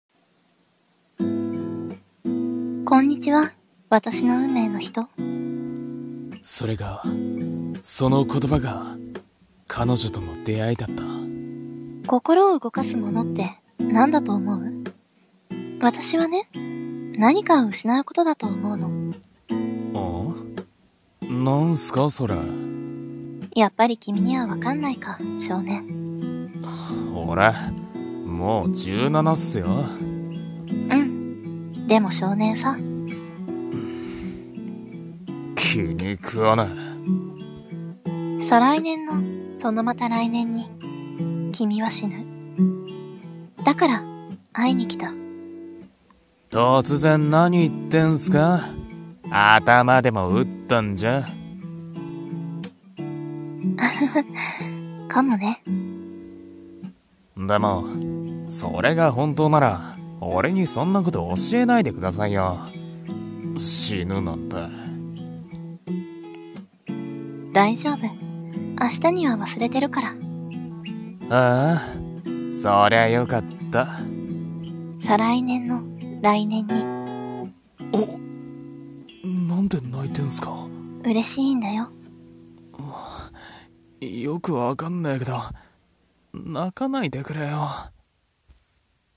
【映画予告風】 再来年の来年に
声劇